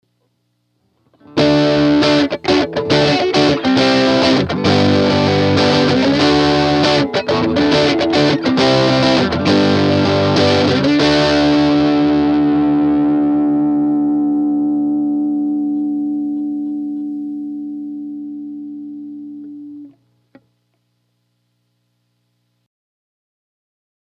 Overdriven with Tube Screamer for Extra Drive (Gibson Nighthawk 2009)
2. Mic angled along speaker cone, 1″ off the grille cloth.
champ_rec_dirty_ang.mp3